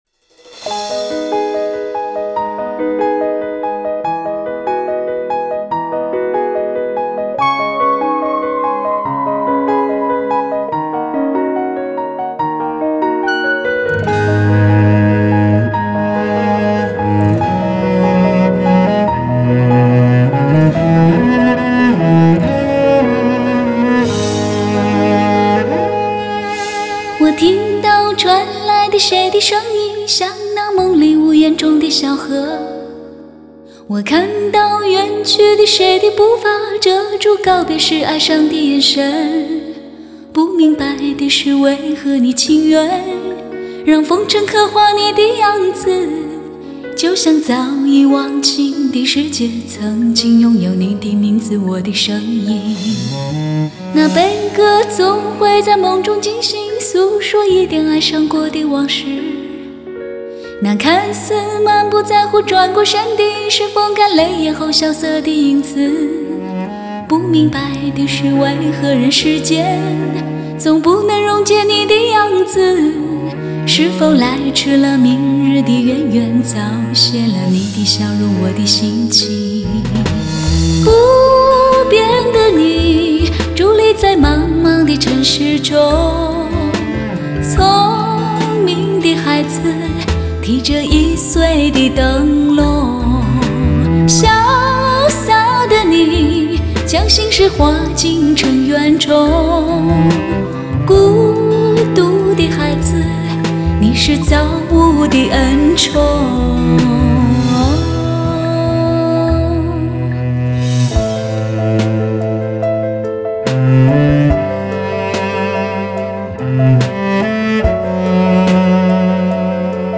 Жанр: Cinese Pop